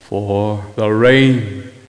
forRain2.mp3